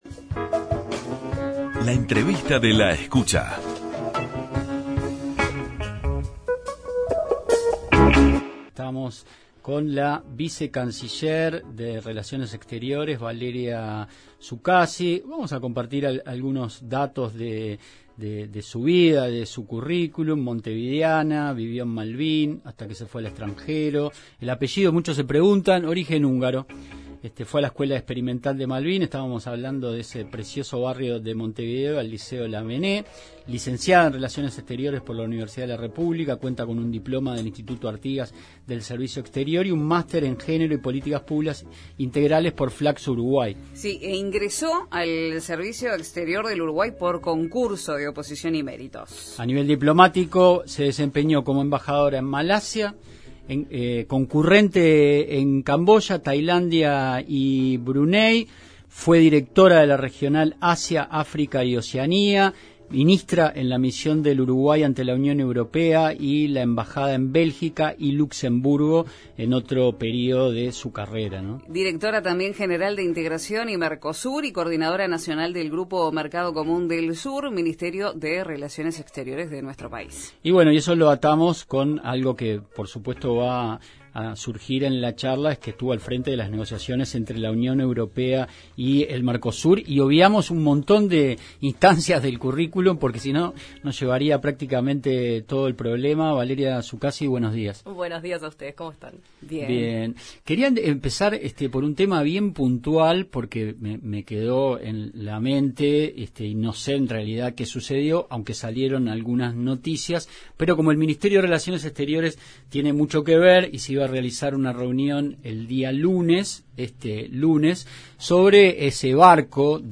Entrevista con la vicecanciller
En diálogo con La Escucha la subsecretaria de Relaciones Exteriores, Valeria Csukasi, pilar fundamental en el proceso de negociación, celebró que el acuerdo se haga realidad el próximo viernes 1 de mayo.